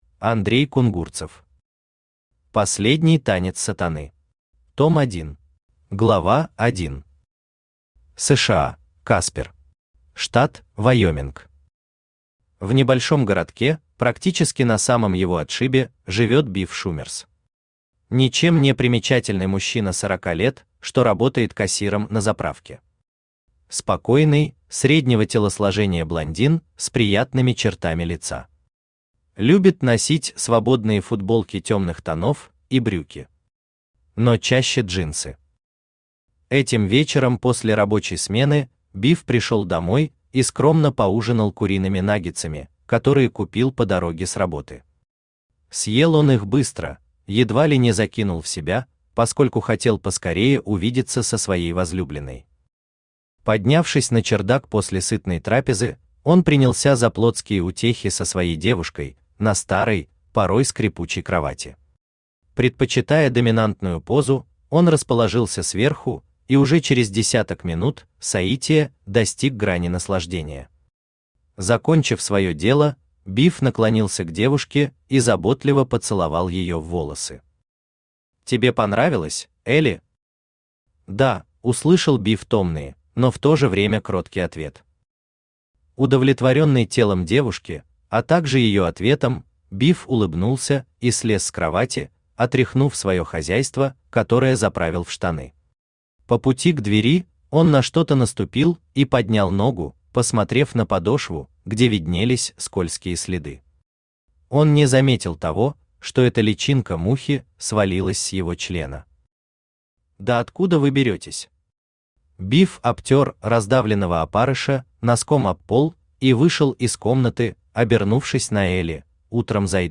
Том 1 Автор Андрей Кунгурцев Читает аудиокнигу Авточтец ЛитРес.